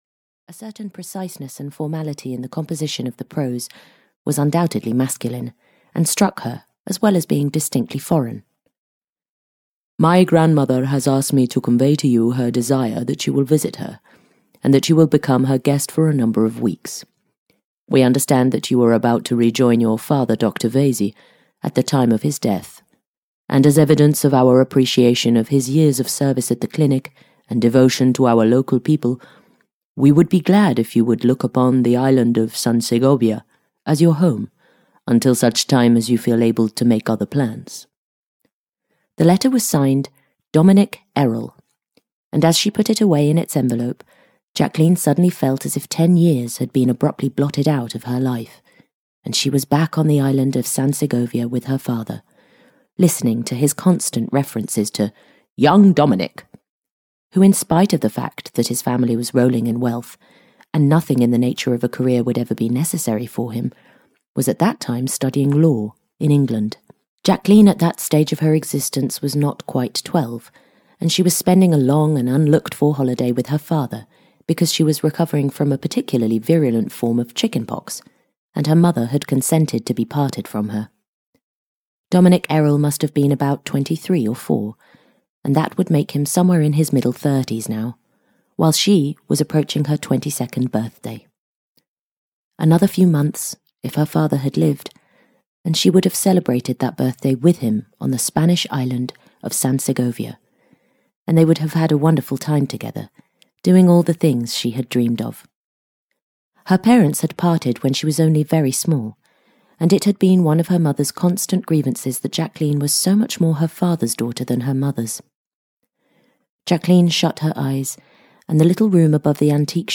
Love is For Ever (EN) audiokniha
Ukázka z knihy